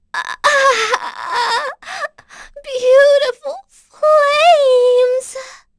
Pansirone-Vox_Dead.wav